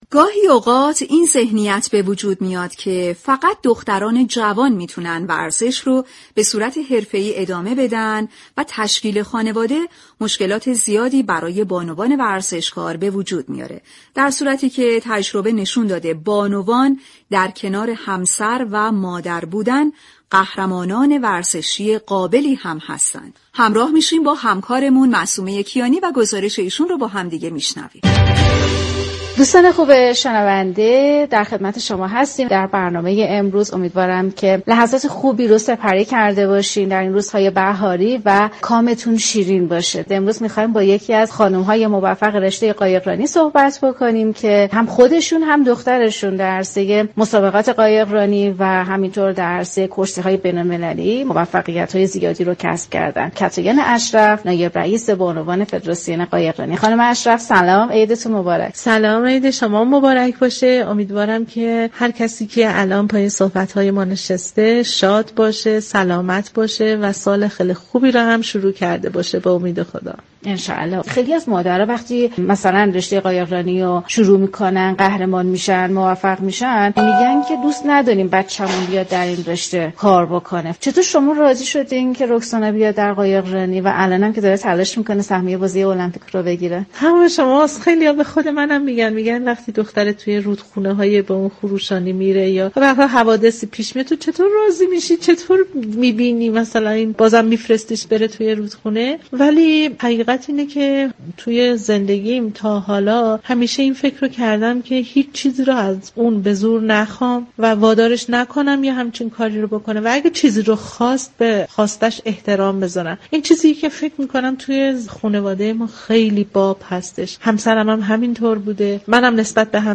مصاحبه با مادر یك خانواده ورزشكار
رویداد